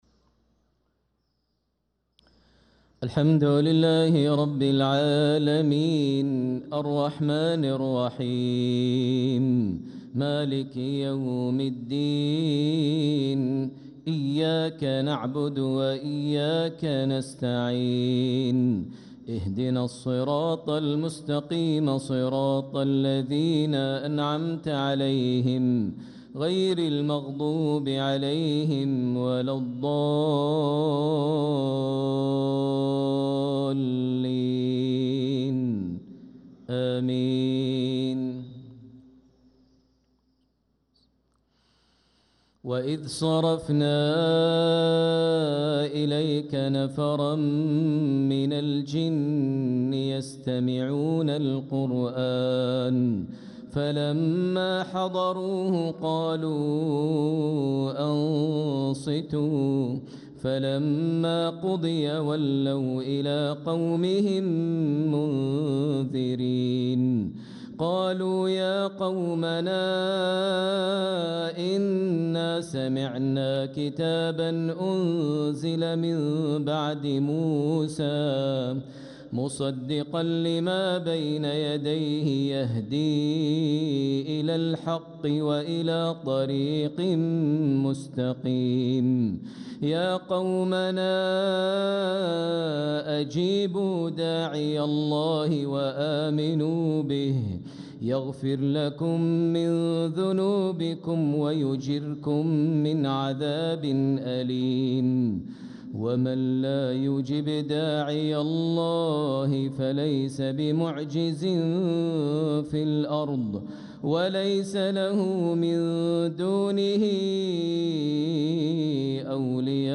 صلاة المغرب للقارئ ماهر المعيقلي 3 جمادي الأول 1446 هـ
تِلَاوَات الْحَرَمَيْن .